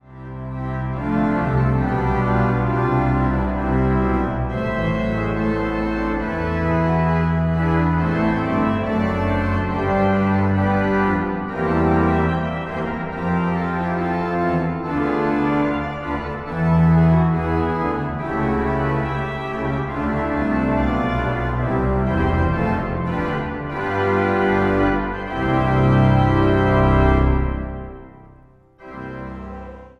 orgel
Zang | Mannenzang
(orgelsolo)